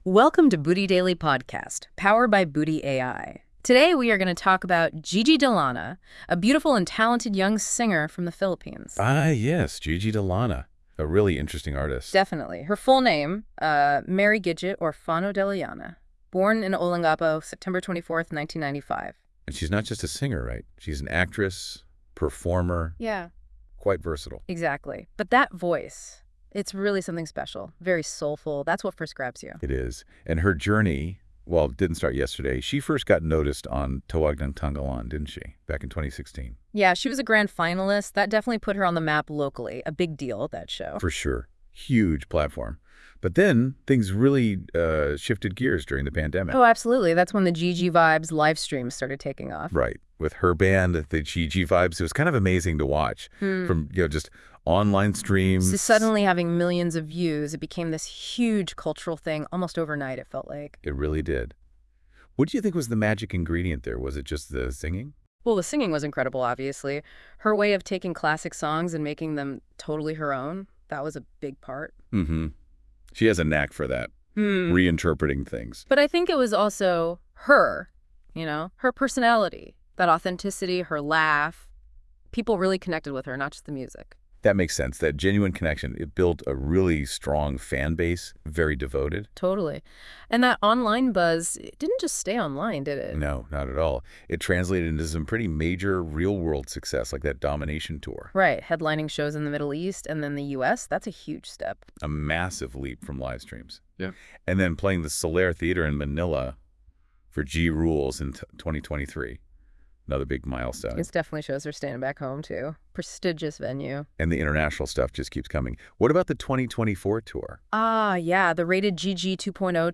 Meet Gigi De Lana—a young and incredibly talented singer and actress from the Philippines who’s capturing hearts across the world with her powerful voice and vibrant personality.
Whether she’s belting out emotional ballads or lighting up the screen with her natural charisma, Gigi continues to inspire fans with her passion, authenticity, and undeniable star quality.
Experience her incredible voice right here.
Gigi-De-Lana_-A-Rising-Filipino-Music-Star.wav